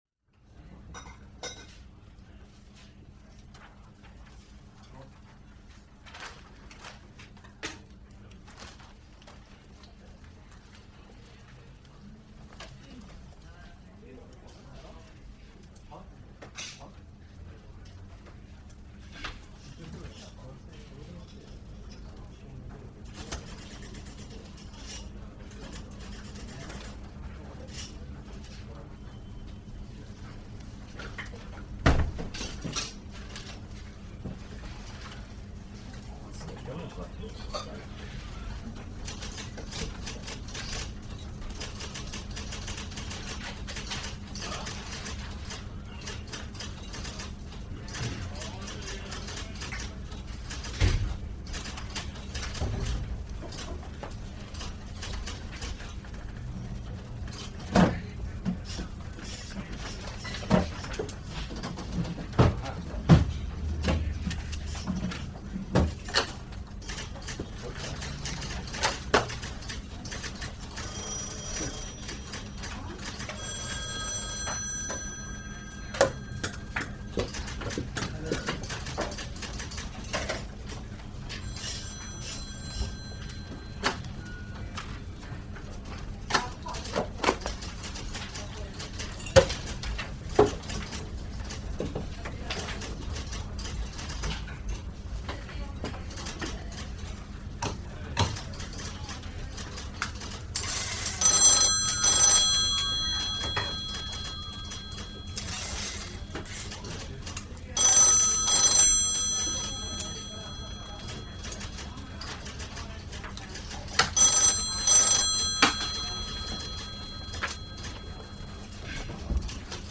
oficina-4-ambiente-tranquilo.mp3